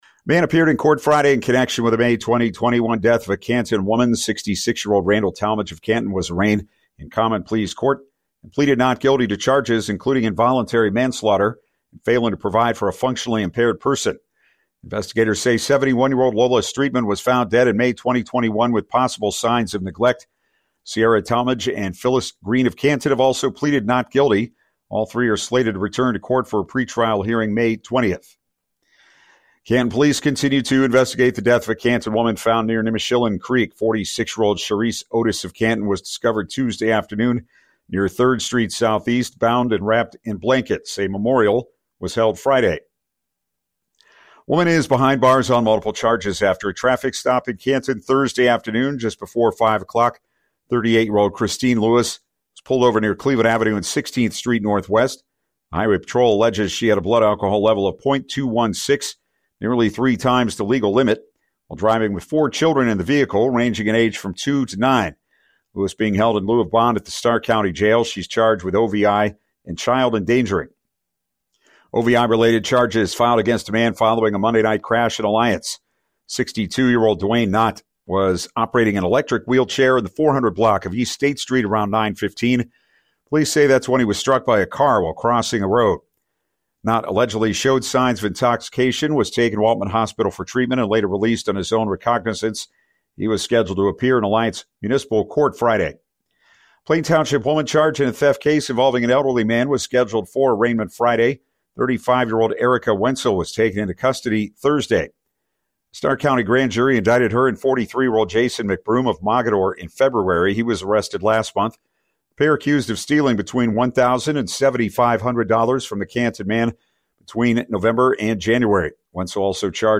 MORNING-NEWS-4.18.mp3